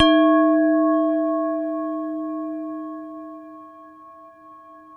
WHINE  D#2-L.wav